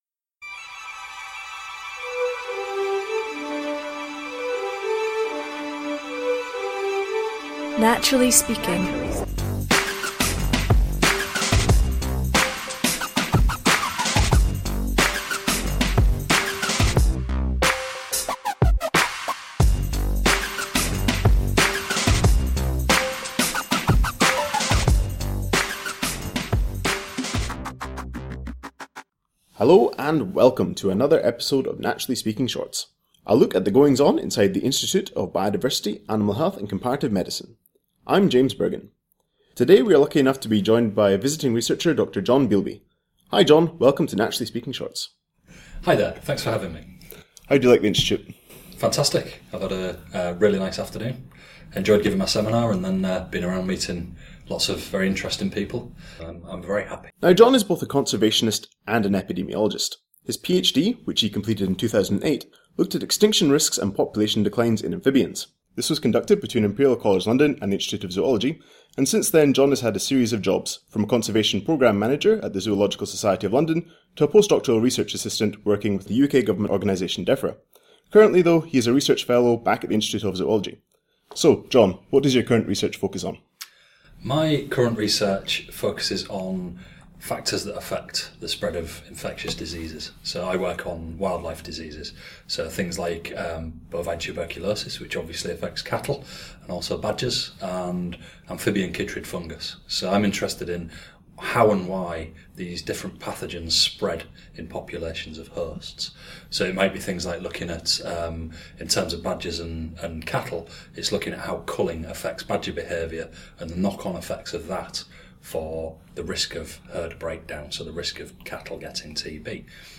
Join us as we discuss everything from big data to field biology, epidemiology to politics, and of course the pure joy and enticement of studying the natural world.